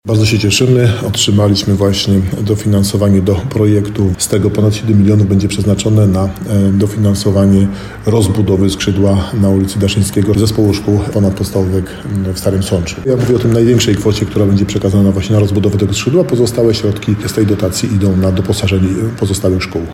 – To dobre wiadomości dla uczniów i nauczycieli – mówi starosta nowosądecki Tadeusz Zaremba.
8starosta.mp3